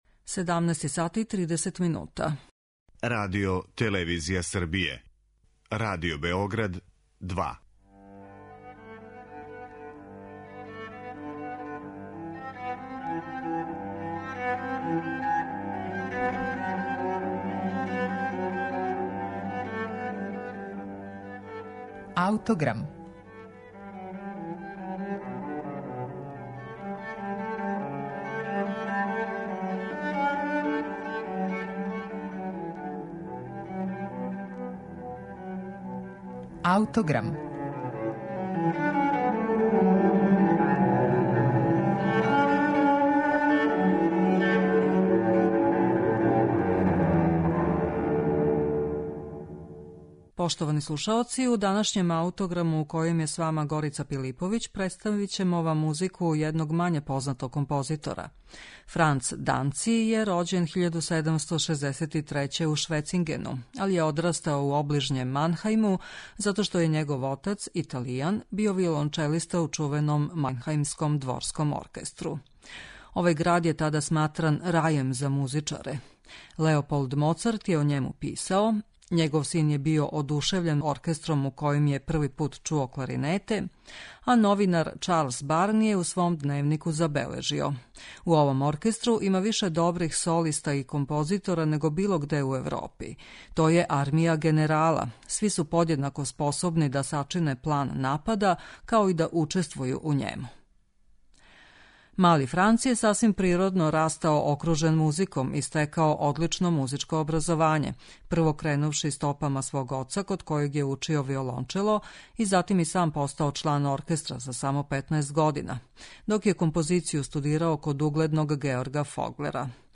концерта за флауту и оркестар